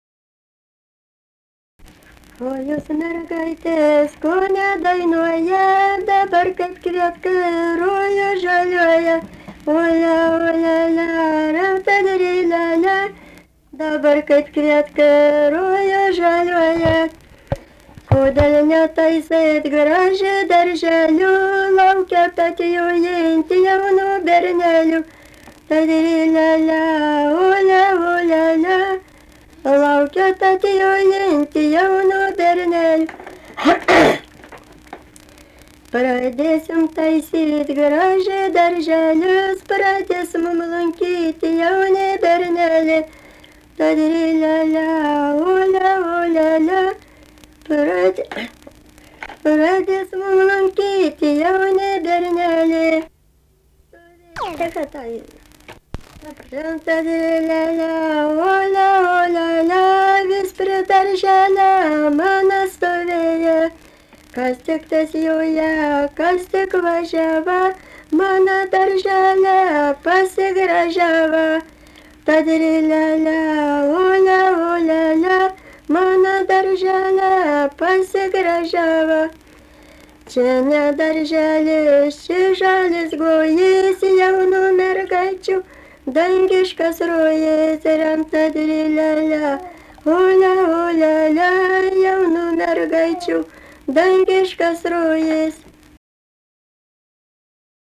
daina, vestuvių
Mantvydai
vokalinis